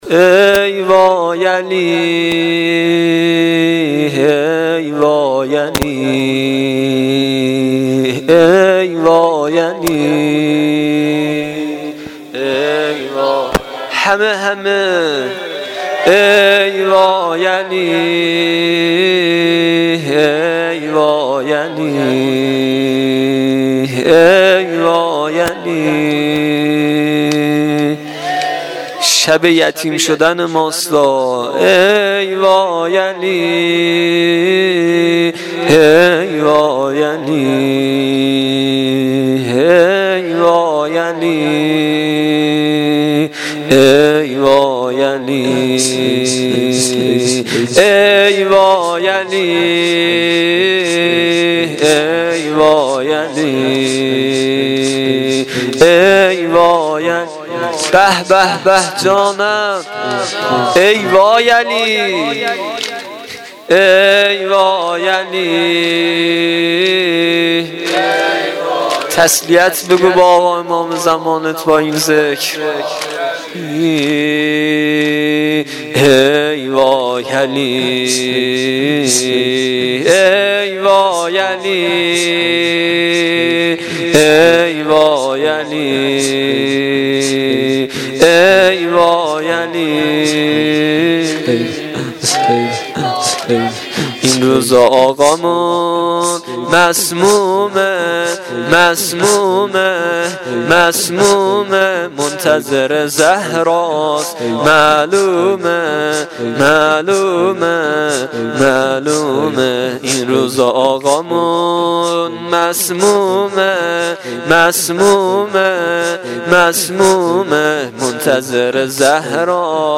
زمینه شب 21 ماه رمضان